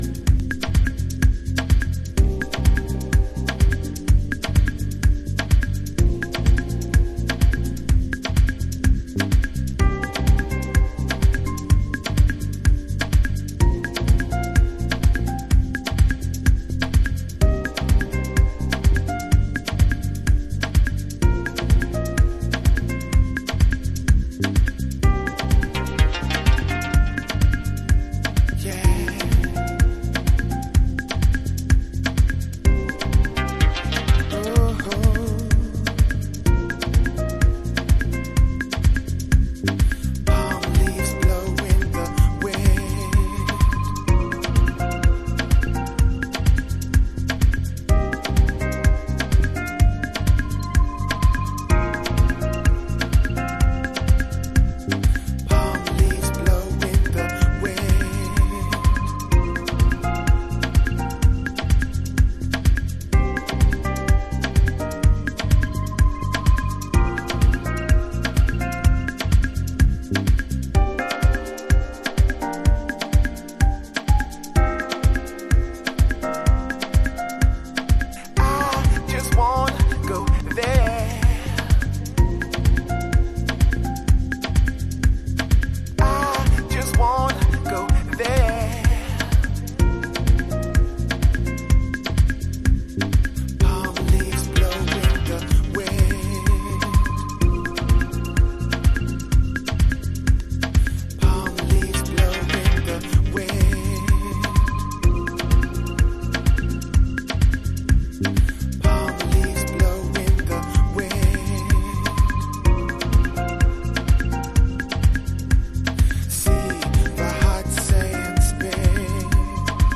深い時間に沁み込みそうなディープハウス。
House / Techno